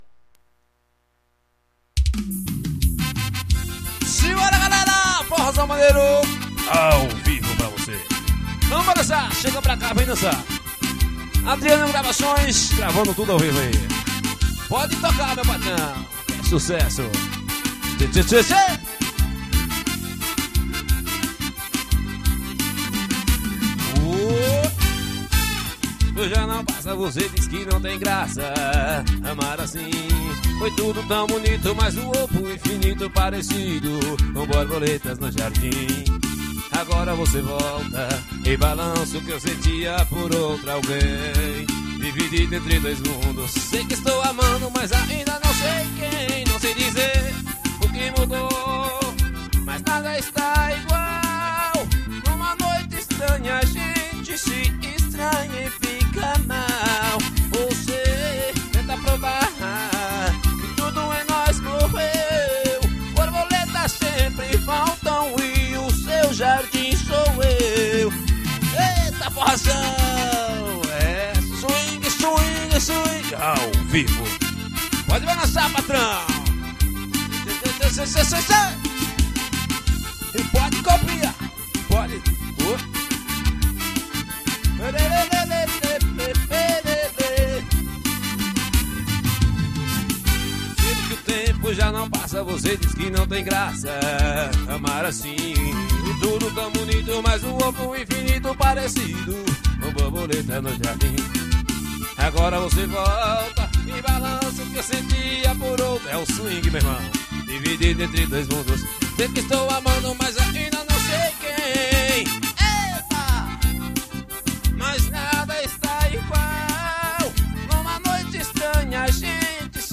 Composição: capitacao ao vivo.